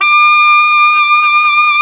SAX ALTOFF0N.wav